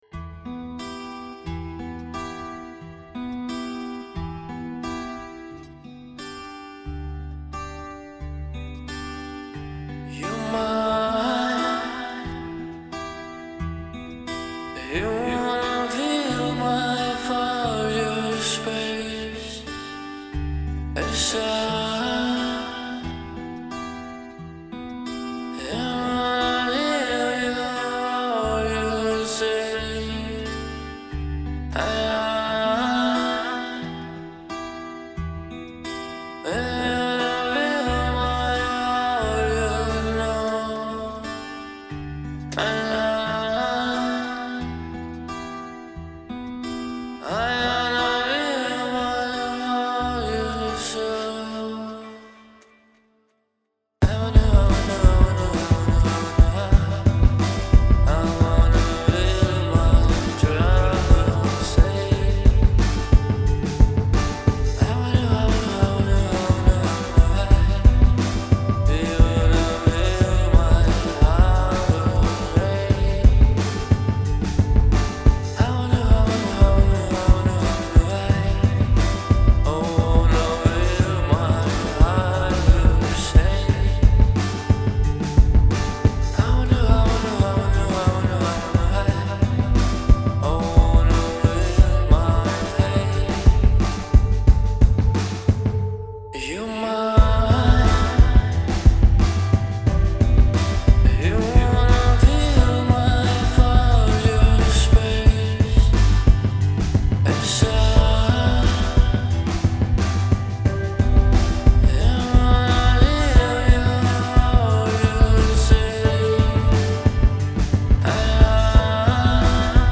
Бочка ватная потому что очень низкая, басовитая и без транзиентов.